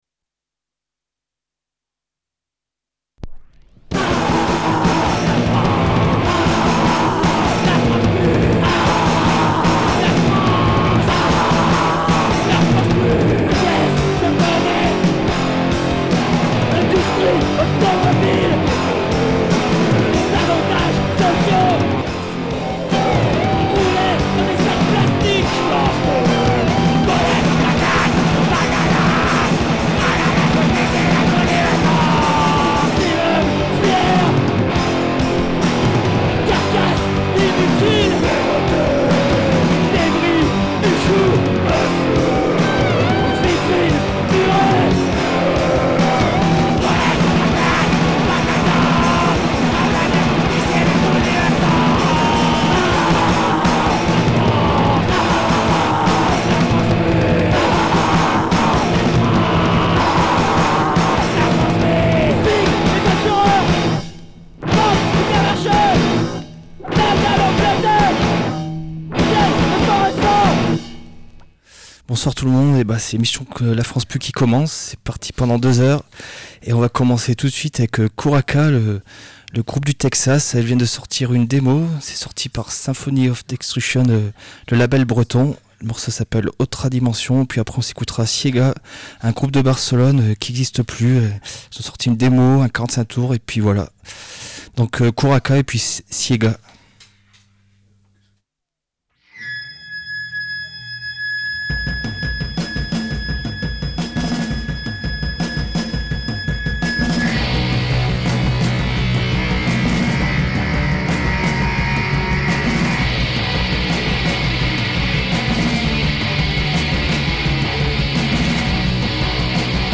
Désolé-es mais en raison de problèmes techniques en-dehors de notre volonté, il manque une dizaine de minutes en fin d’émission (partie en italique)
Sorry, but due to technical reasons, something like 10 minutes are missing at the end of the show (italic part)